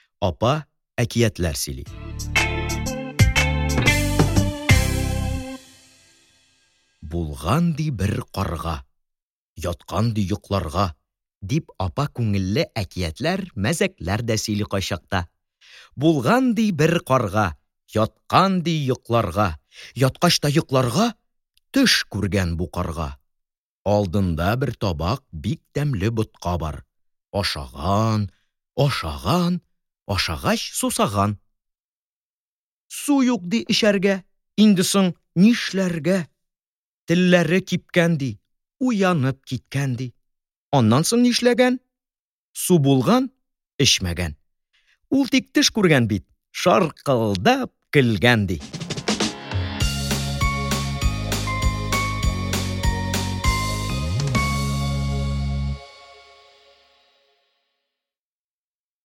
Aудиокнига Шигырьләр Автор Бари Рәхмәт